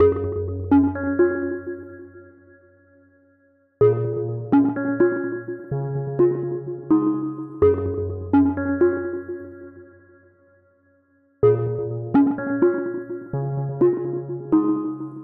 Tag: 126 bpm Trap Loops Bells Loops 2.56 MB wav Key : Unknown FL Studio